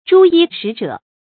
朱衣使者 zhū yī shǐ zhě
朱衣使者发音
成语注音 ㄓㄨ ㄧ ㄕㄧˇ ㄓㄜˇ